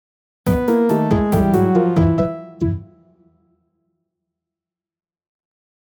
Retro track for transitions & stingers.